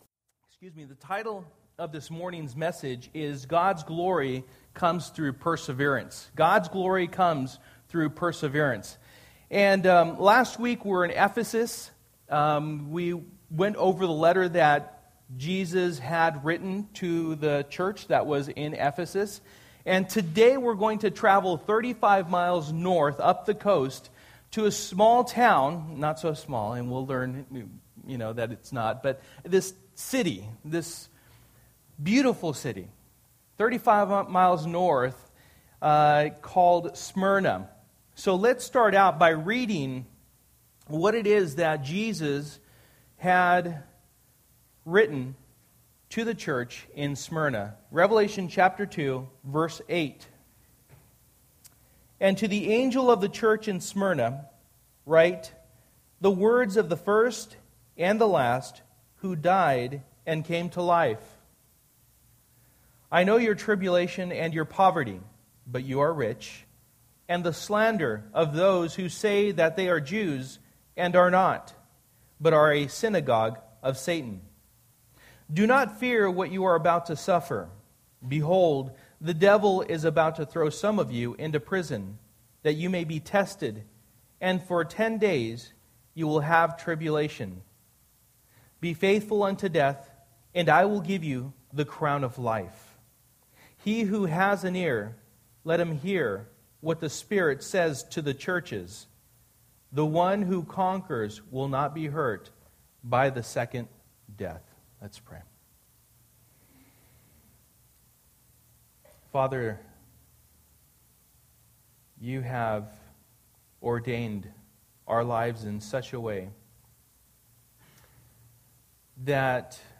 Passage: Revelation 2:8-11 Service: Sunday Morning